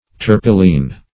Search Result for " terpilene" : The Collaborative International Dictionary of English v.0.48: Terpilene \Ter"pi*lene\, n. (Chem.)